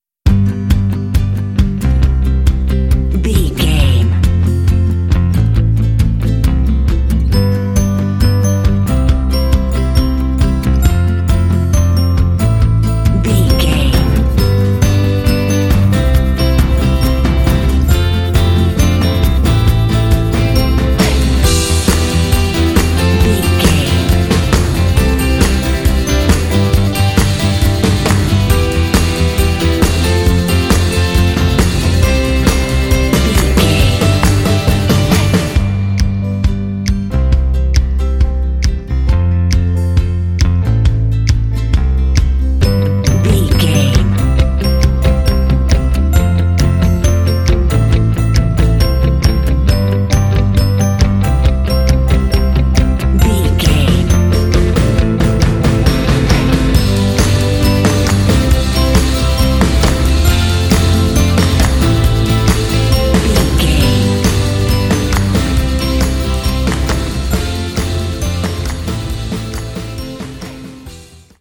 Fun and cheerful indie track with bells and “hey” shots.
Uplifting
Ionian/Major
playful
acoustic guitar
electric guitar
bass guitar
drums
piano
alternative rock
contemporary underscore